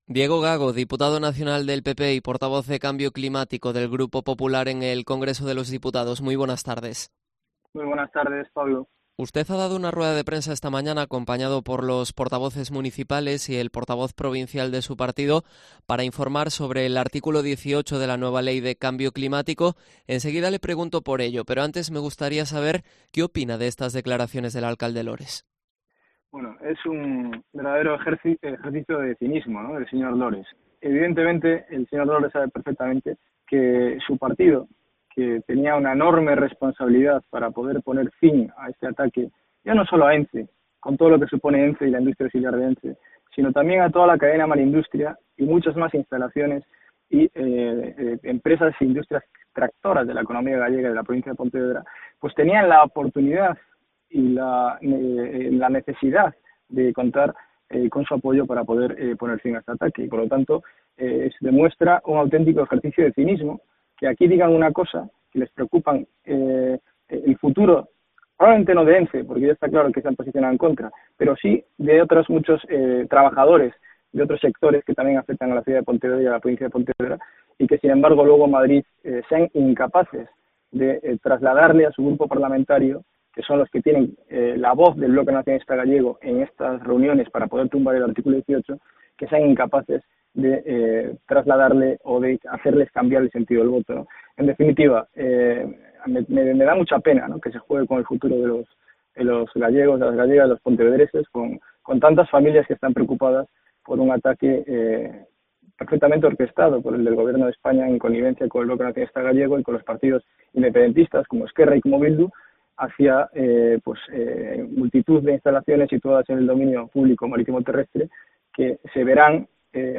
Entrevista a Diego Gago, portavoz de Cambio Climático del GPP en el Congreso